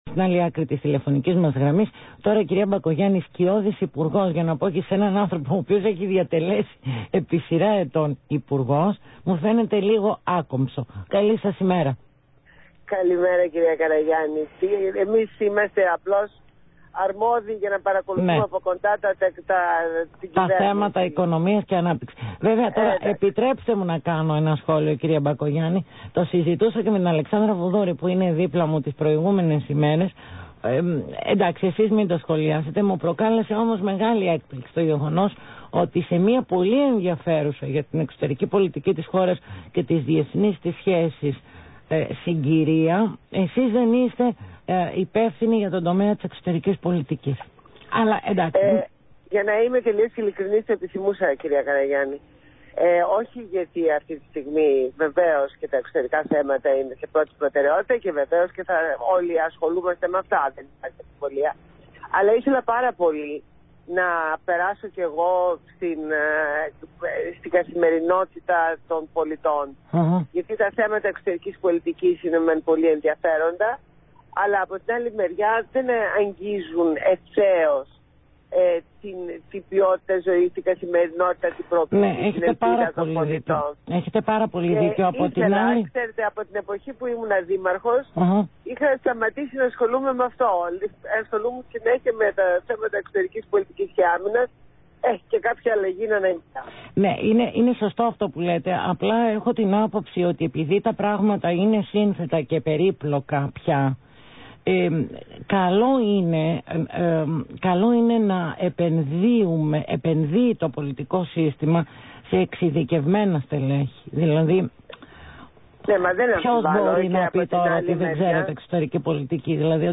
Συνέντευξη στο ραδιόφωνο Αθήνα 9,84